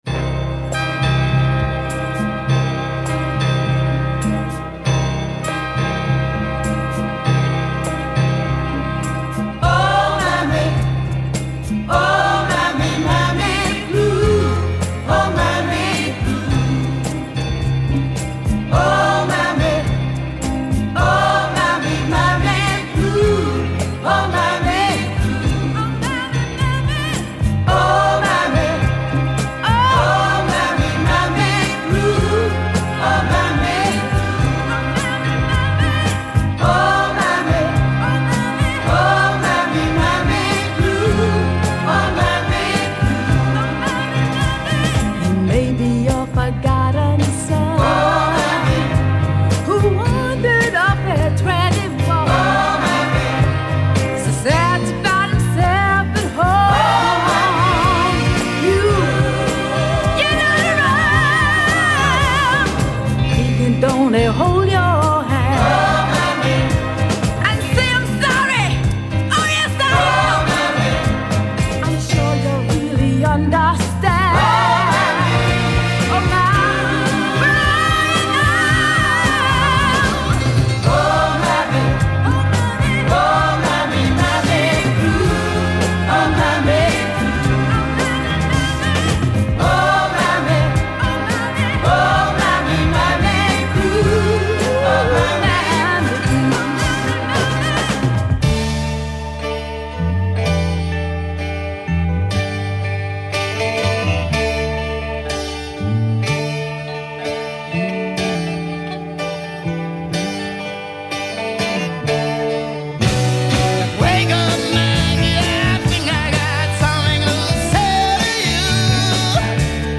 Тоже несколько композиций в одной.